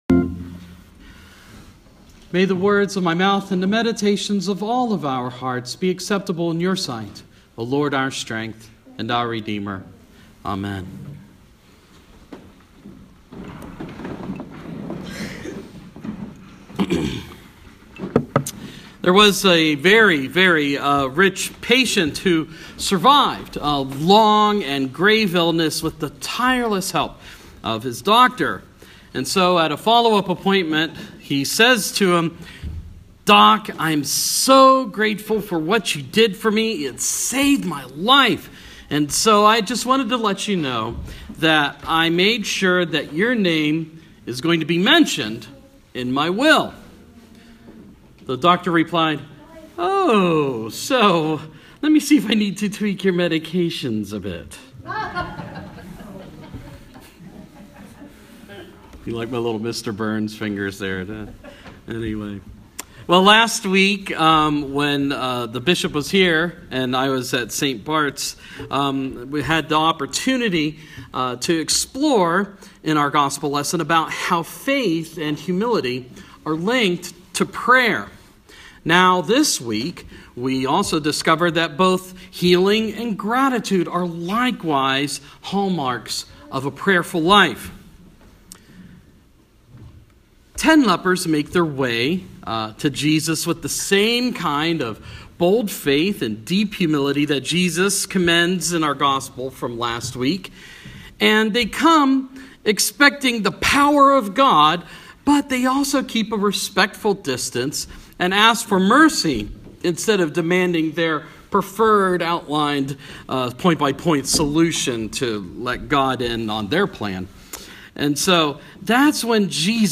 Sermon – Proper 23 – 2016